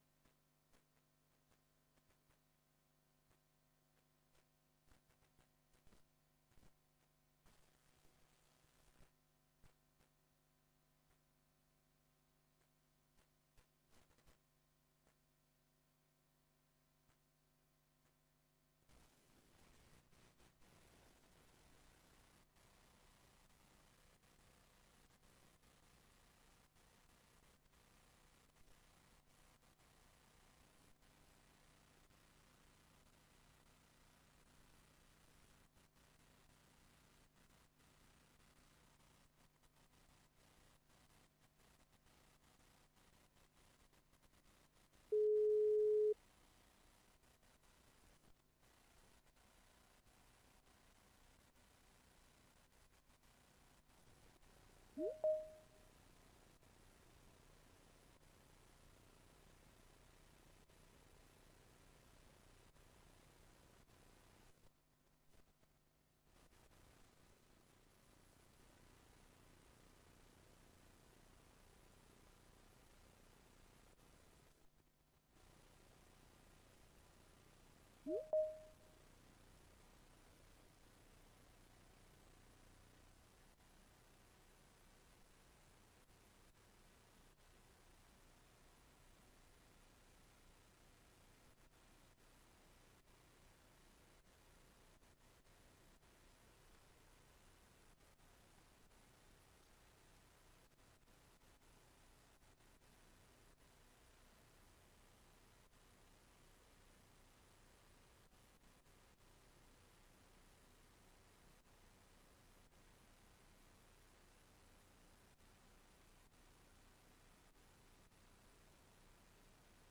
Locatie: Raadszaal